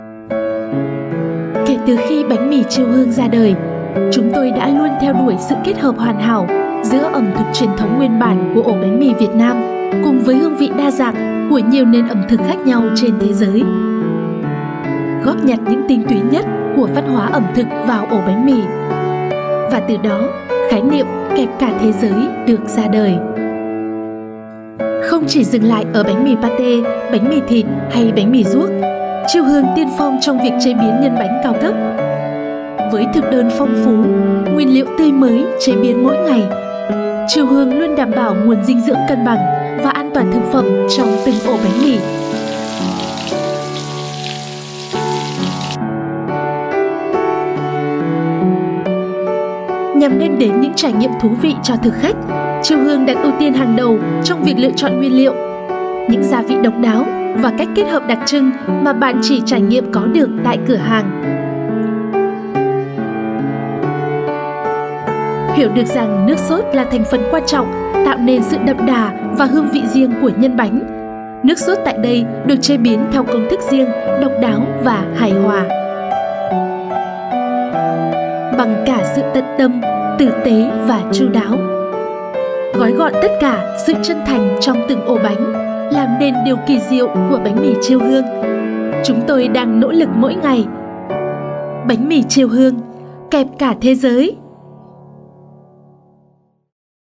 VIETNAMESE NORTH FEMALE VOICES
We use Neumann microphones, Apogee preamps and ProTools HD digital audio workstations for a warm, clean signal path.